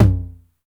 Index of /90_sSampleCDs/Club-50 - Foundations Roland/KIT_xTR909 Kits/KIT_xTR909 3
TOM XC.TOM03.wav